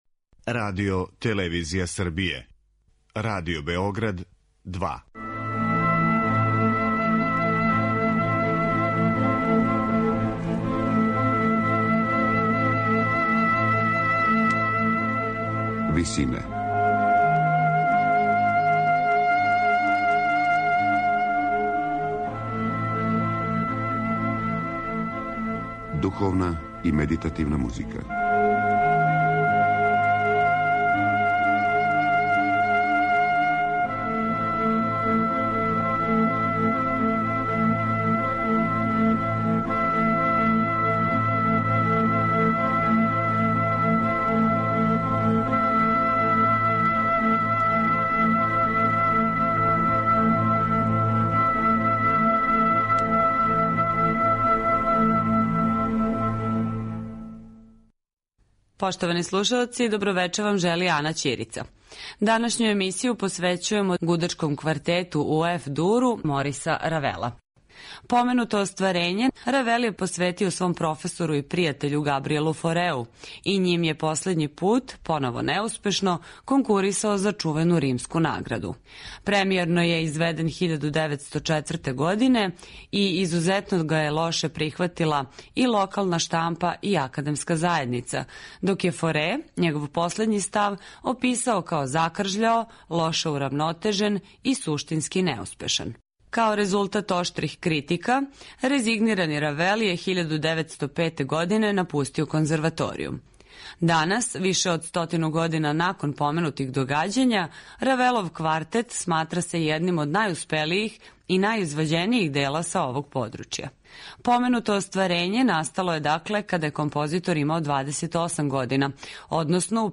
Гудачки квартет